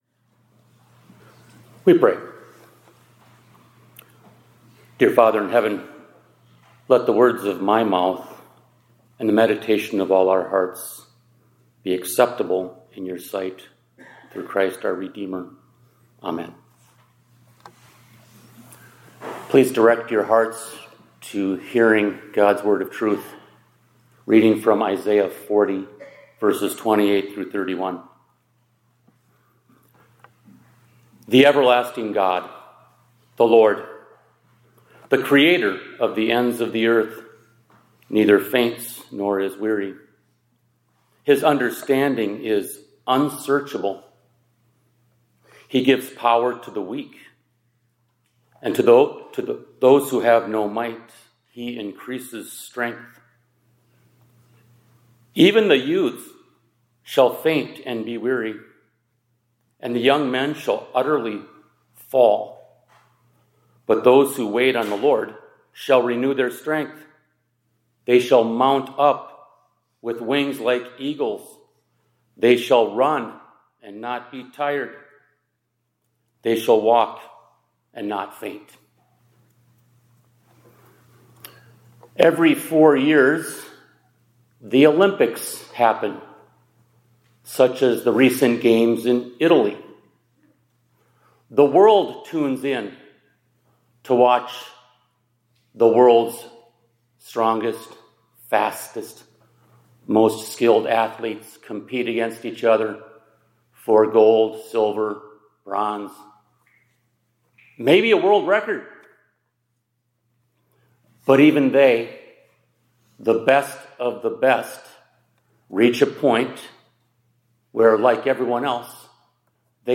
2026-02-23 ILC Chapel — How the Strength of God Becomes Our Own